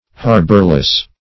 Harborless \Har"bor*less\, a. Without a harbor; shelterless.